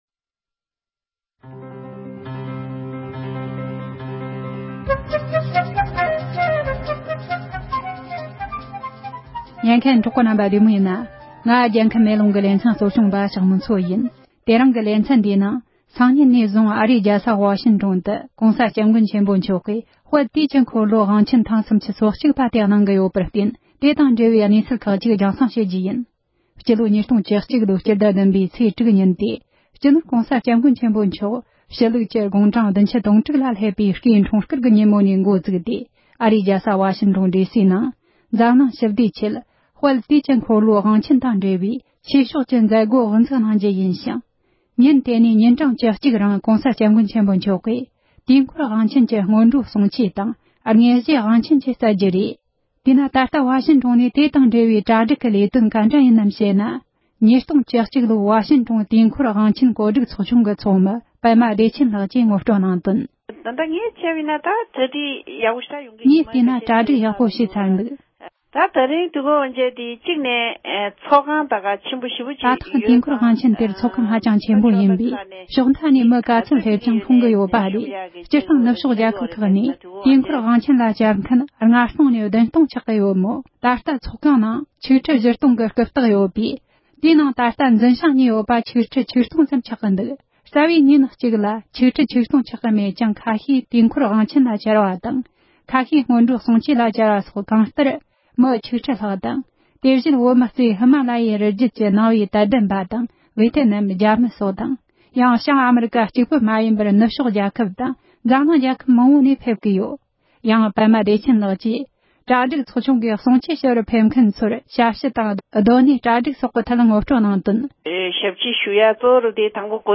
ཕྱི་ལོ་༢༠༡༡ལོའི་ལྦ་ཤིང་ཊོན་དུས་འཁོར་དབང་ཆེན་གྱི་གྲྭ་སྒྲིག་སོགས་ཀྱི་སྐོར་བཅར་འདྲི་ཞུས་པ།